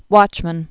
(wŏchmən)